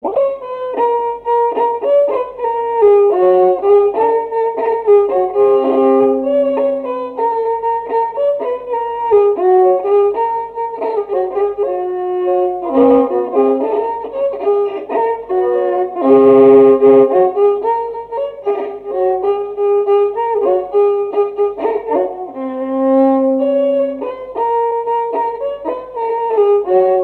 Genre strophique
répertoire d'un violoneux
Pièce musicale inédite